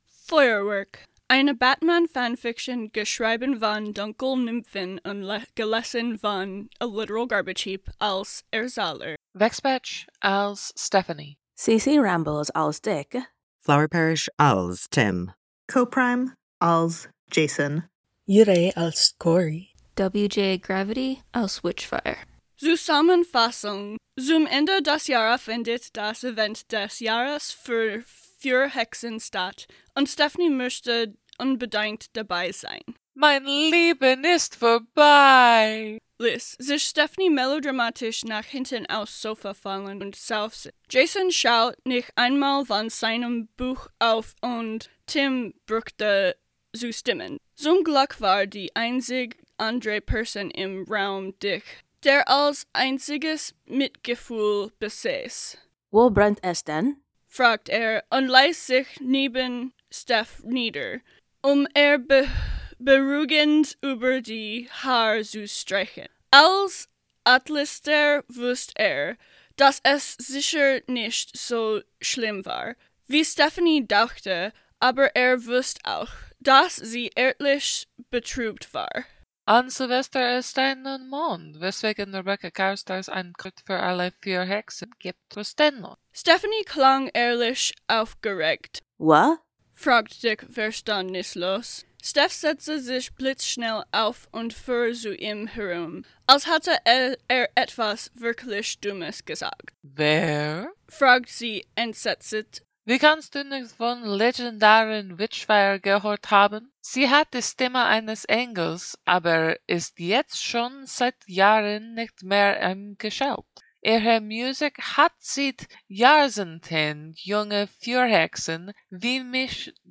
collaboration|ensemble, info|collaboration
Version, gelesen und aufgenommen von Menschen, die kein oder wenig Deutsch sprechen: